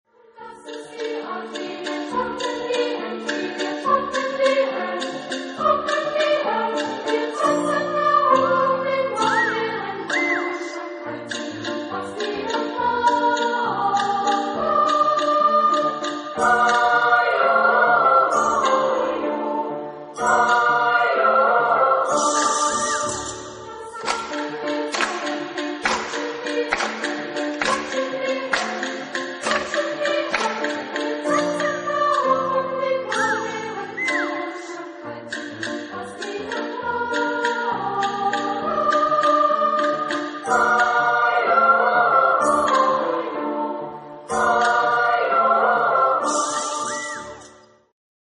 Type de choeur : SSA  (3 voix égale(s) d'enfants )
Tonalité : ré mineur